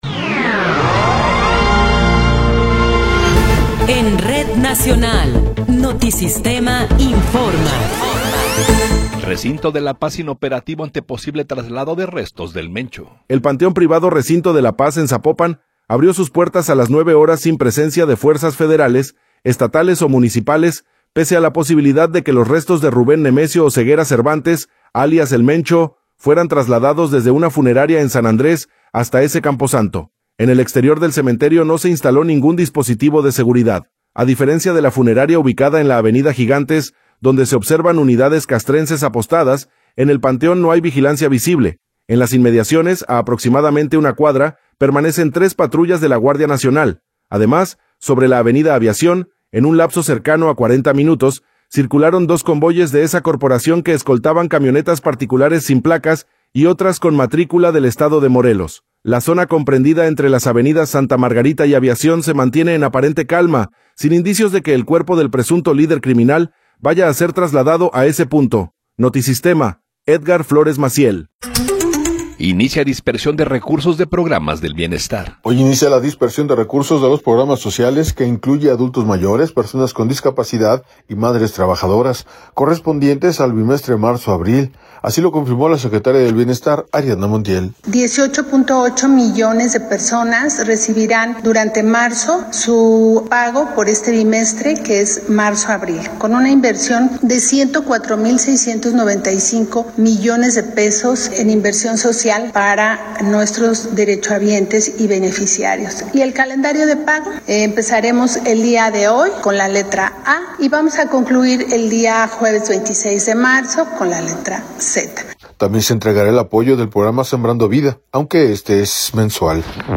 Noticiero 11 hrs. – 2 de Marzo de 2026
Resumen informativo Notisistema, la mejor y más completa información cada hora en la hora.